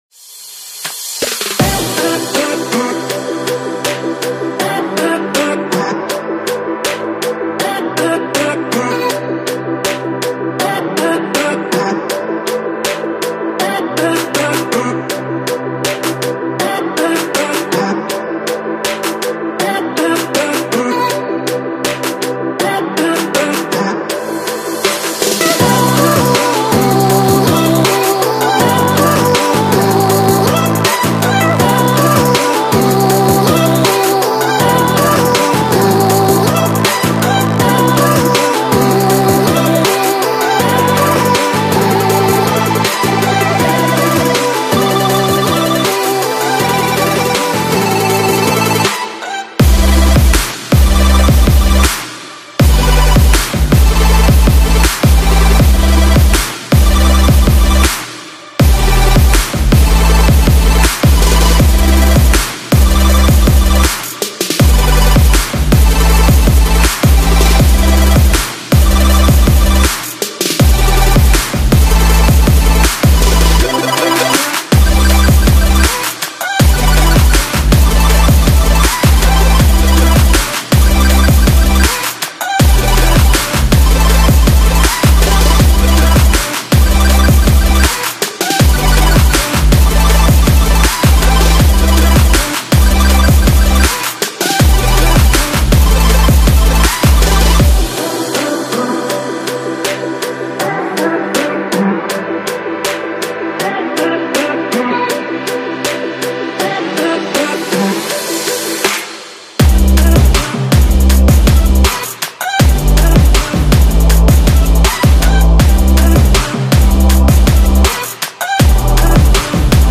Trap, Dreamy, Laid Back, Euphoric, Happy